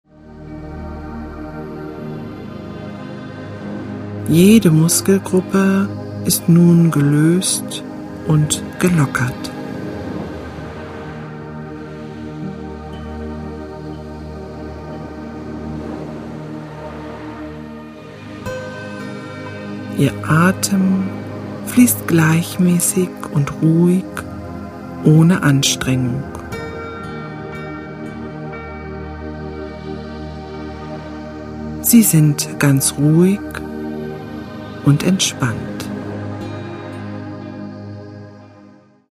Musik: N.N.